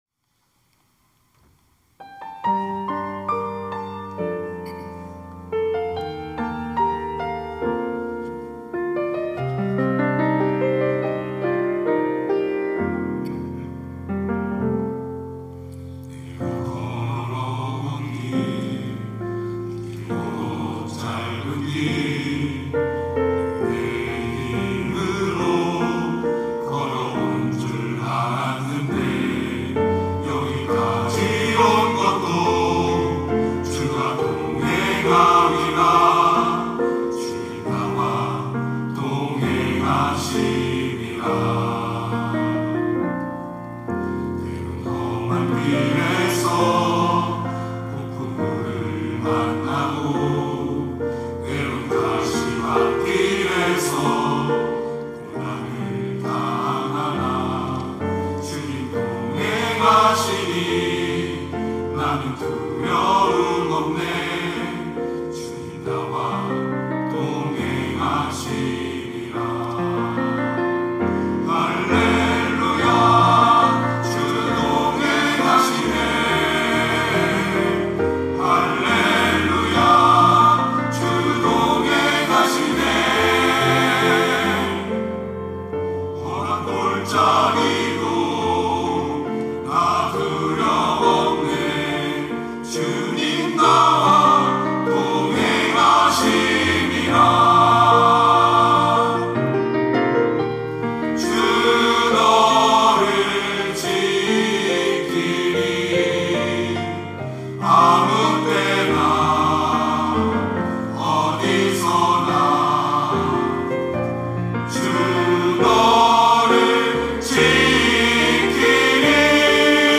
특송과 특주 - 동행
교역자